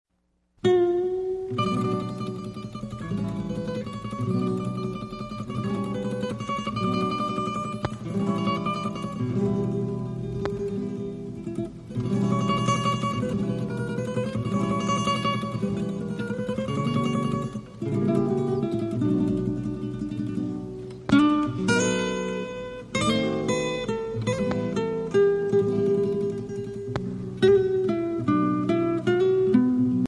حنجره‌ی گرم